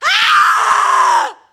scream_woman_3.ogg